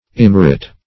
Immerit \Im*mer"it\, n. Lack of worth; demerit.